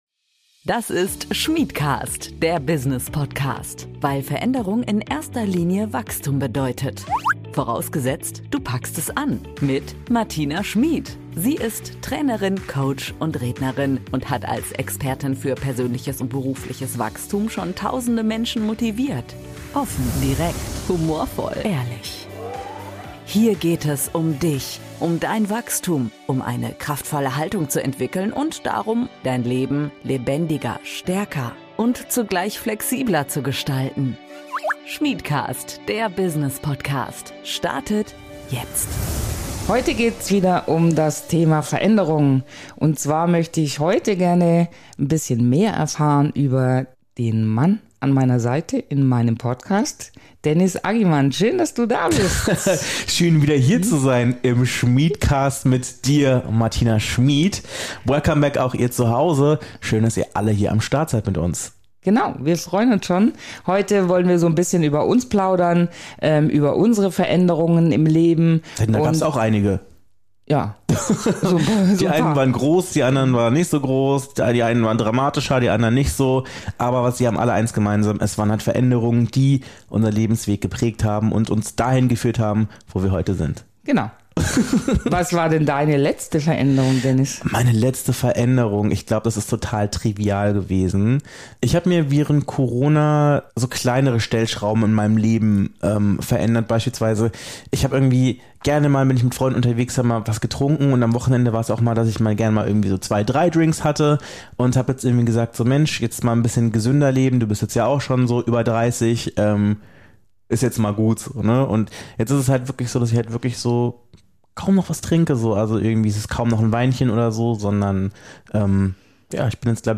Die beiden plaudern aus dem Nähkästchen und zeigen tolle Beispiele für positive Veränderungen, die nachhaltig gut tun.